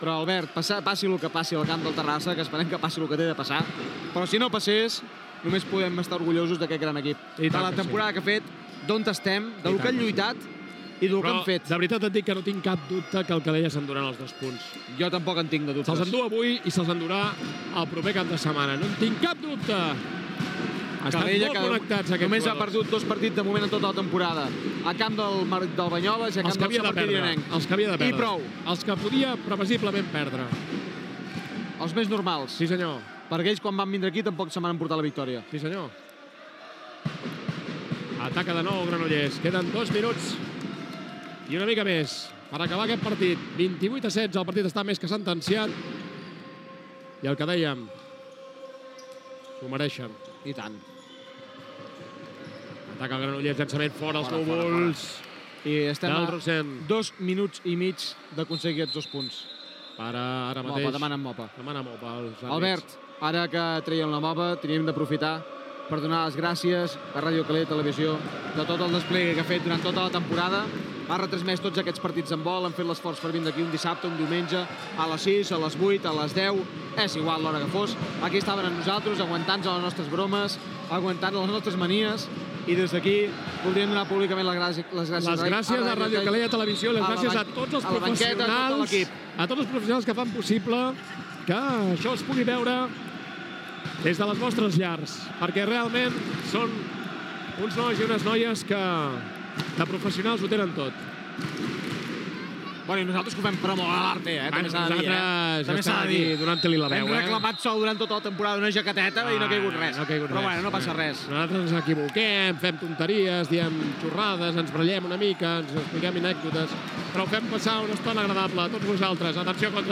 30e8f138b95844d8ebe053f1f4cb0f8664b9ae43.mp3 Títol Ràdio Calella Televisió Emissora Ràdio Calella Televisió Titularitat Pública municipal Descripció Narració dels últims minuts del partit d'habdbol masculí de la Lliga Catana entre la Unió Esportiva Handbol Calella i KH-7 BM Granollers des del pavelló municipal d’esports Parc Dalmau. Entrevistes finals i comiat Gènere radiofònic Esportiu